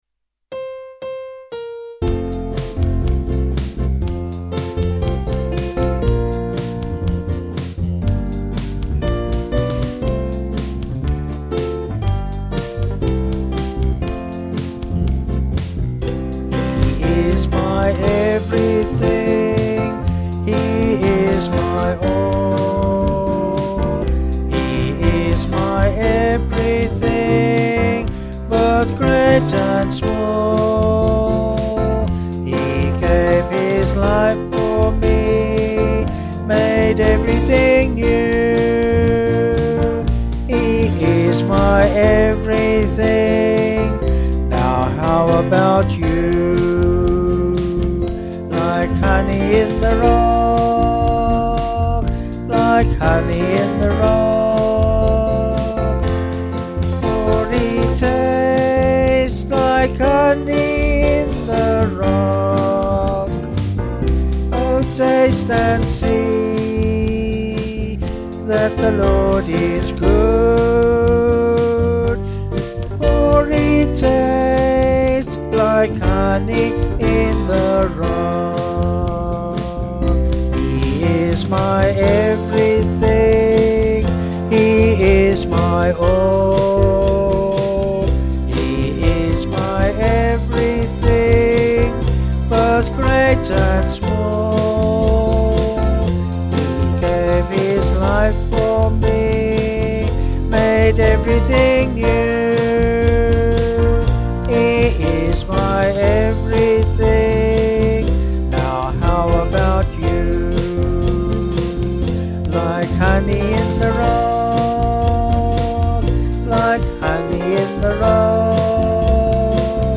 Vocals & Band